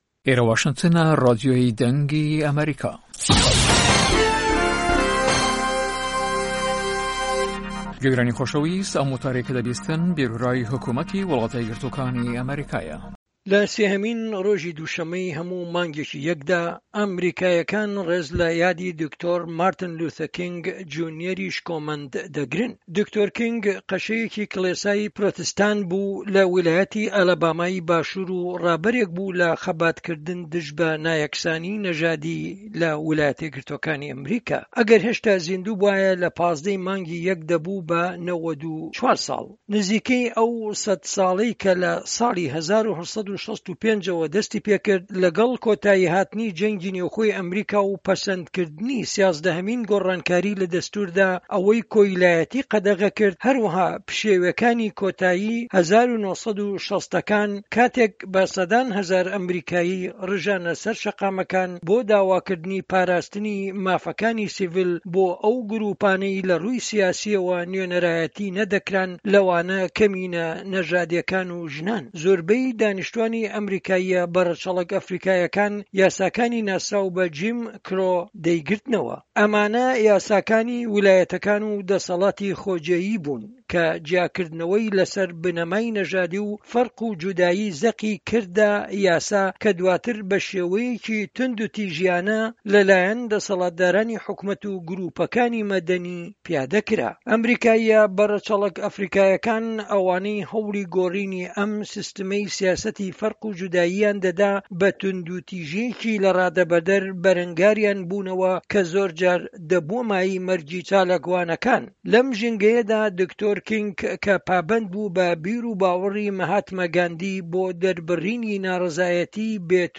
ئەم وتارەی لە دەنگی ئەمەریکا بڵاوکراوەتەوە ڕاوبۆچوونی حکومەتی ئەمەریکایە لە سێهەمین ڕۆژی دووشەممەی هەموو مانگێکی یەکدا، ئەمەریکاییەکان ڕێز لە یادی دکتۆر مارتن لوثەر کینگ جونیەری شکۆمەند دەگرن .